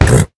latest / assets / minecraft / sounds / mob / horse / hit3.ogg